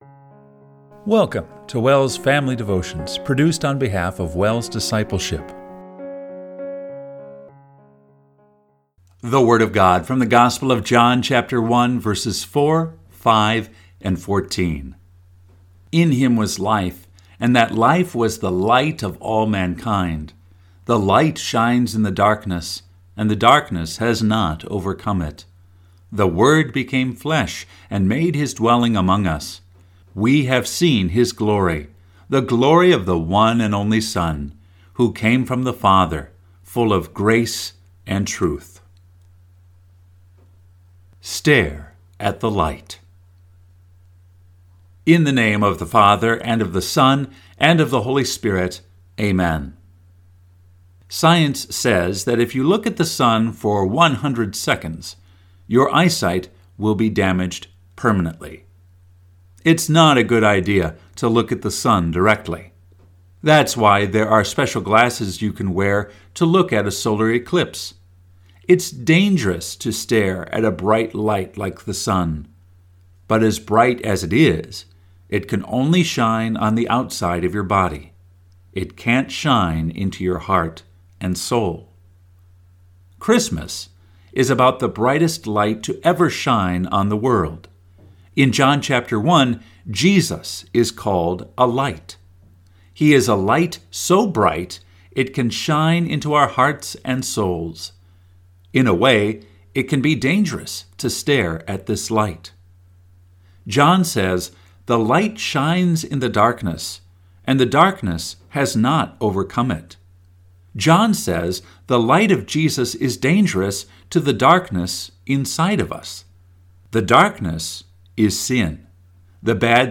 Family Devotion – December 27, 2024